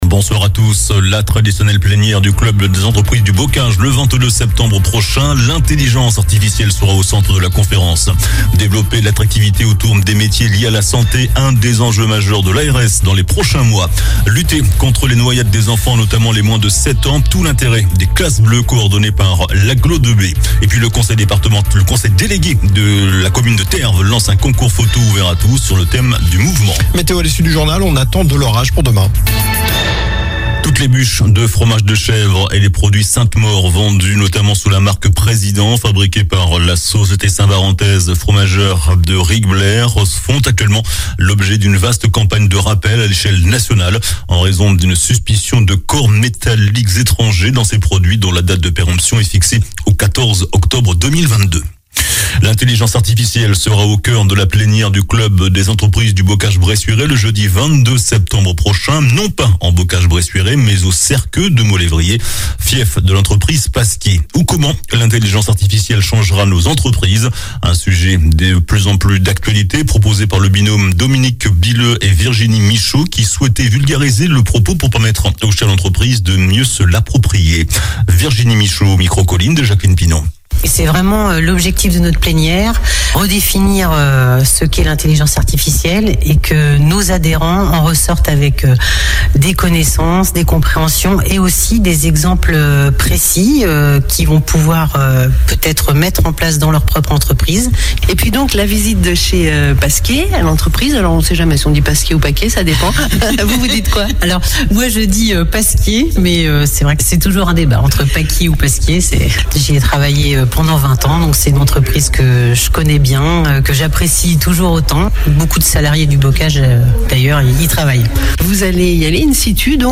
Journal du lundi 12 septembre (soir)